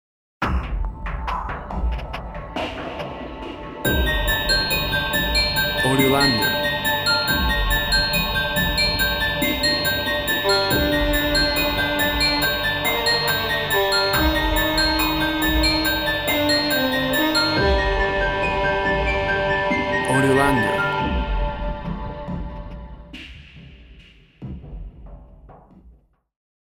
Musical piece induction of fear and terror.
Tempo (BPM) 70